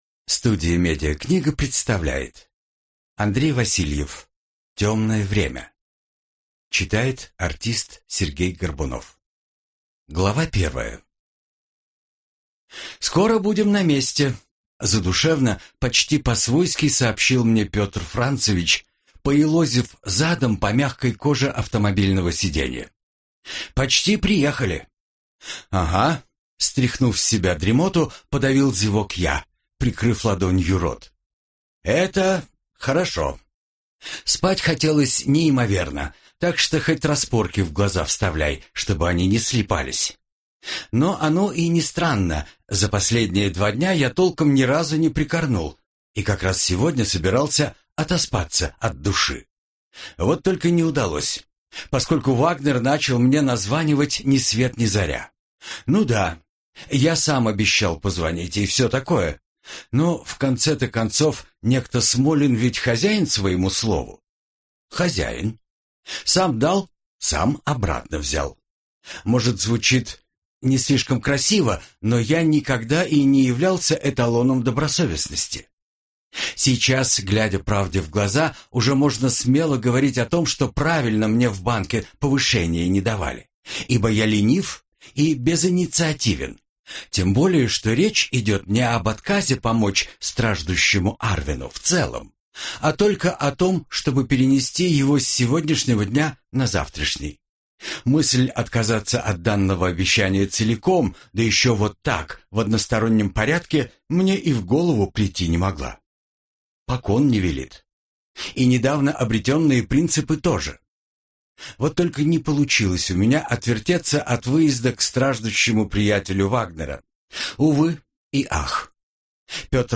Аудиокнига Темное время | Библиотека аудиокниг
Прослушать и бесплатно скачать фрагмент аудиокниги